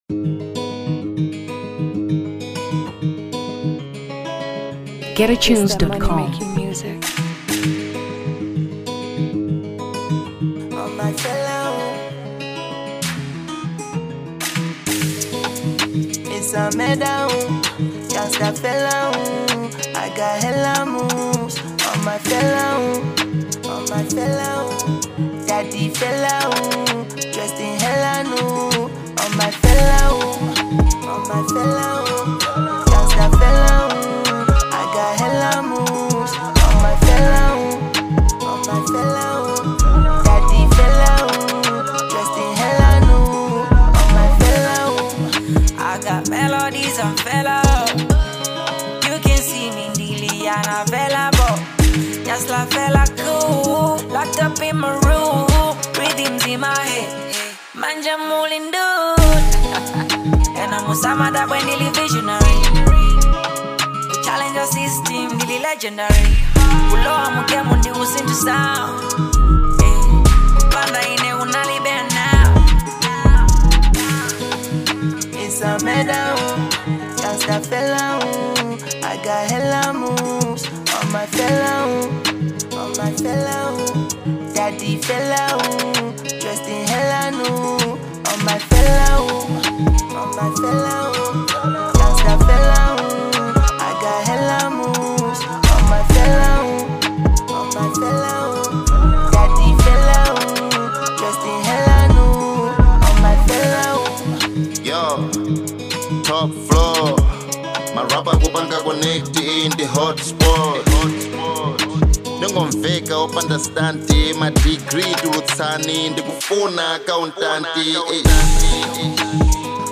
Hip Hop 2023 Malawi